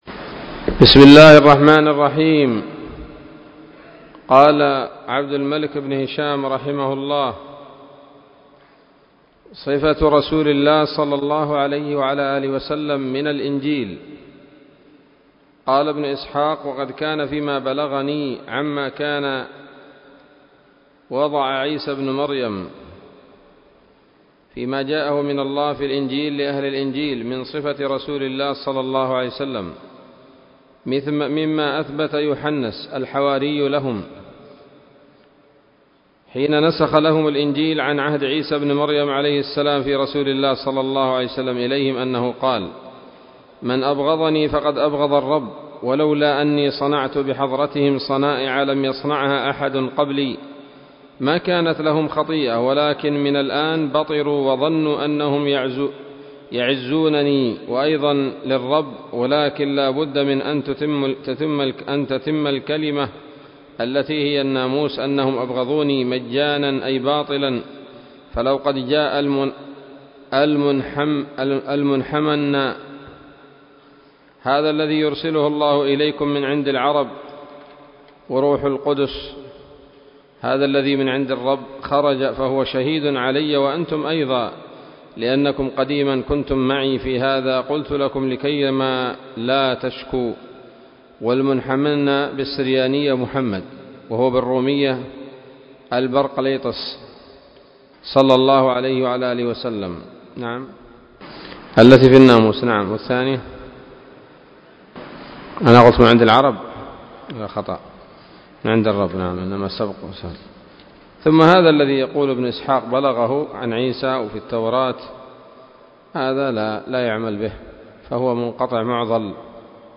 الدرس الثاني والعشرون من التعليق على كتاب السيرة النبوية لابن هشام